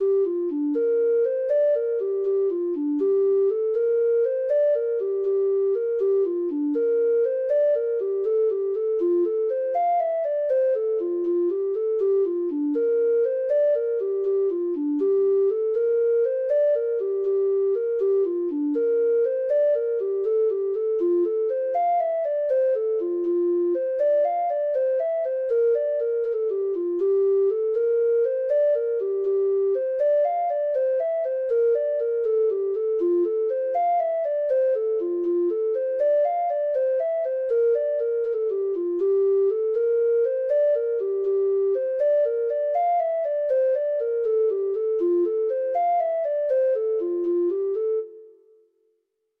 Treble Clef Instrument version
Irish Jigs